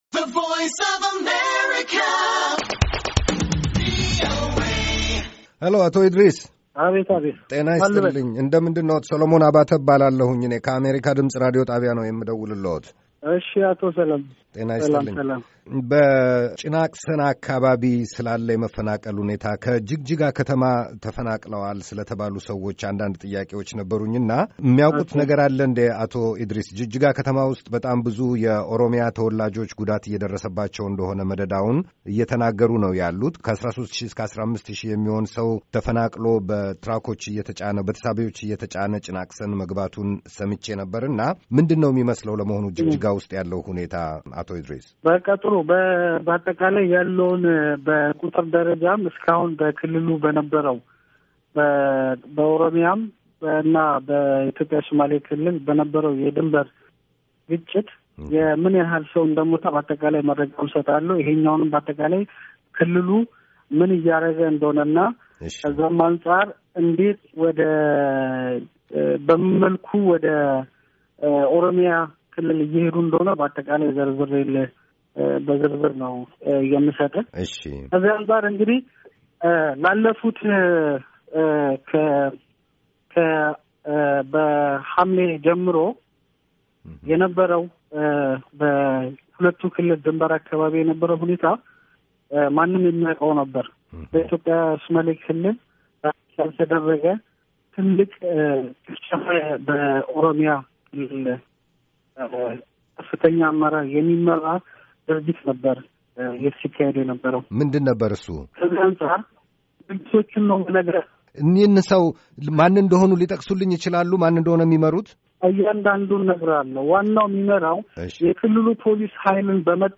ከሶማሌ ክልል የመንግሥት ኮምዩኒኬሽንስ ጉዳዮች ቢሮ ኃላፊ አቶ እድሪስ እስማኤል ጋር የተደረገ ቃለ ምልልስ